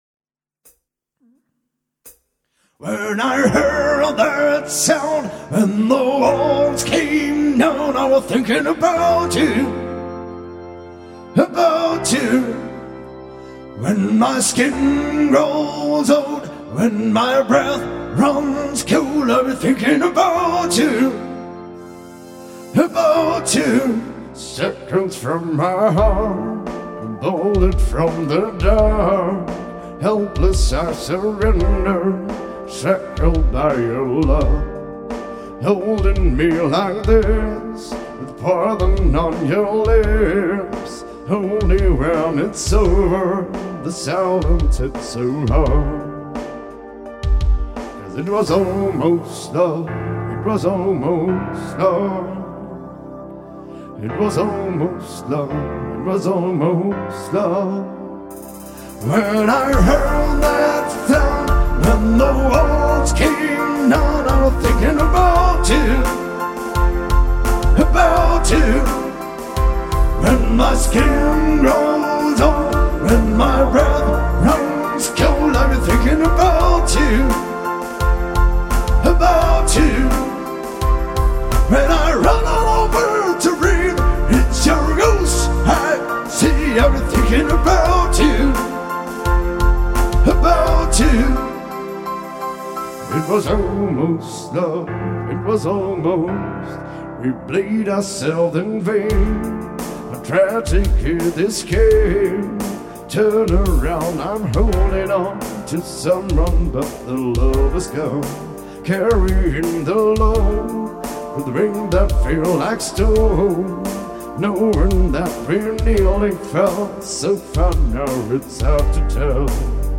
Pop: